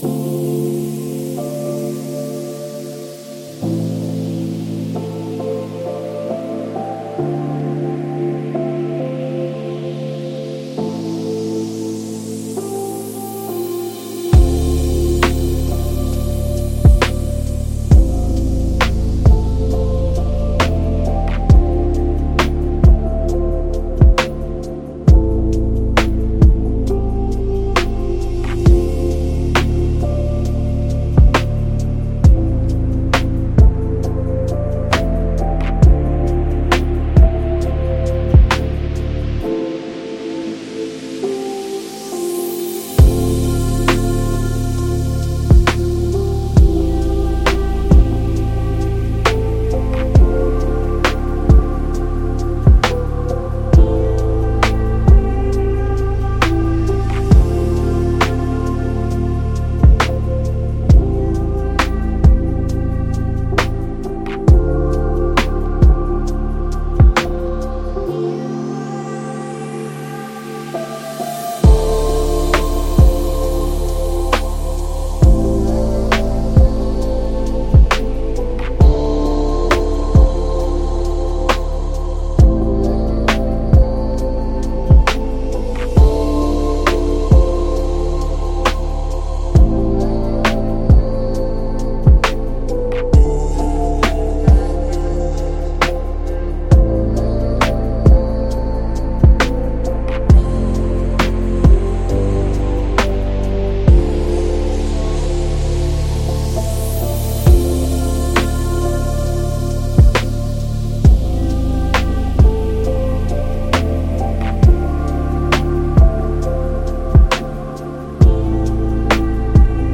Chill Lo Fi